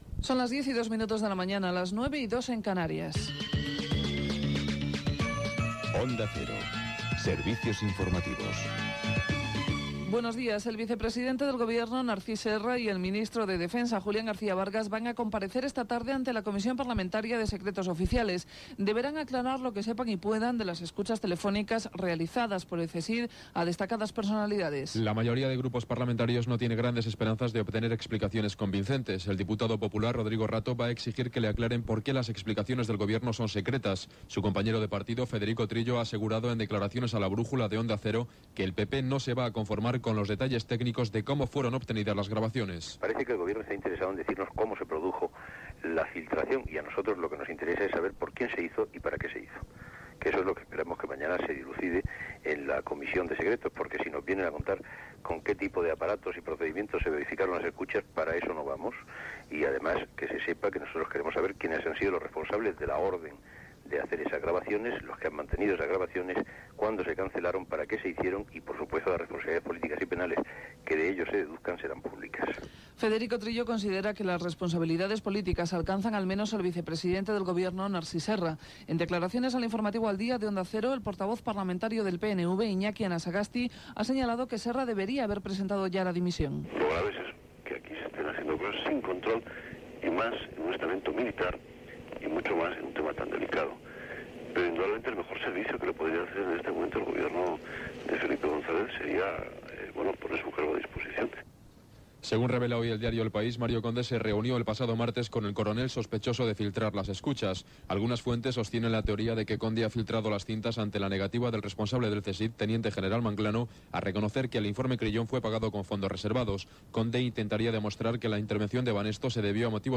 Careta del programa, compareixença del govern espanyol a la Comissió de Secrets del Parlament per explicar les escoltes telefòniques del Centro Superior de Información de la Defensa (CSID), vaga de metges, operació de l'estret de Gibraltar, conflicte de Txetxènia. Publicitat i indicatiu de la ràdio
Informatiu